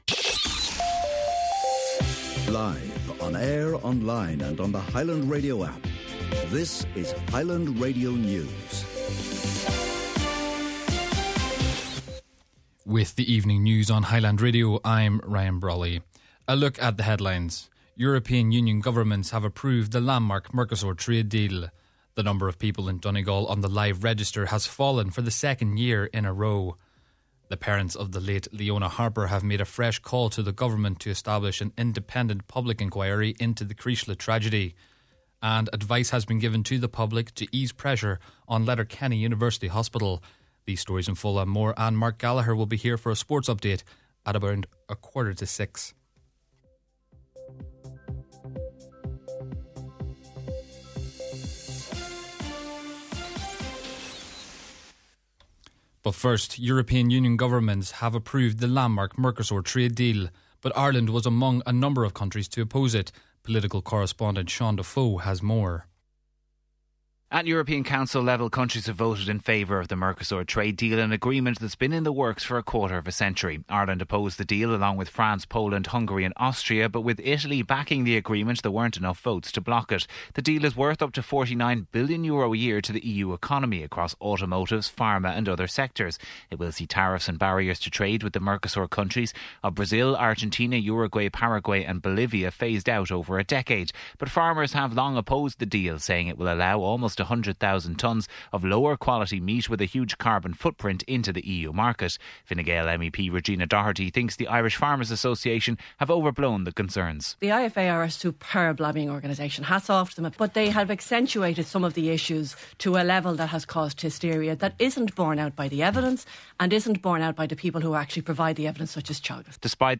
Main Evening News, Sport, an Nuacht and Obituary Notices – Friday January 9th